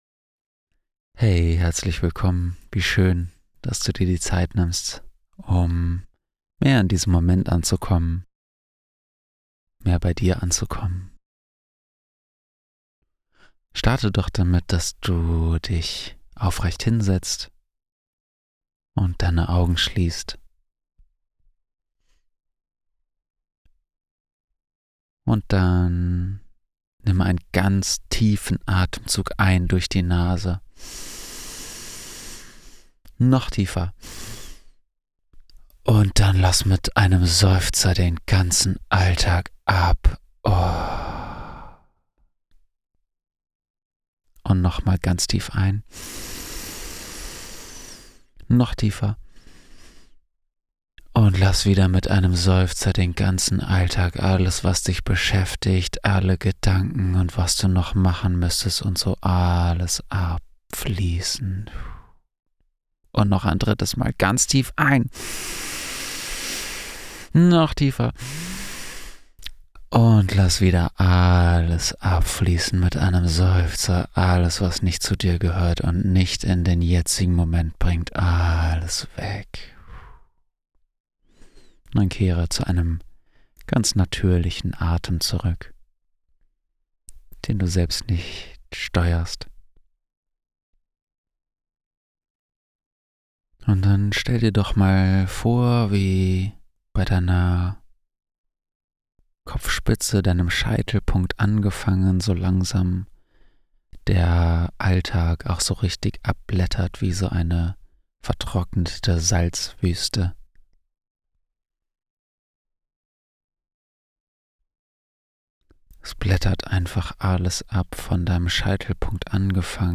Mit dieser Meditation haben wir auch unseren gemeinsamen Abend beim CONSCIOUS CONCERT gestartet.
AnkommensmediAbblaetternWirbelsaeuleWurzelnLicht.mp3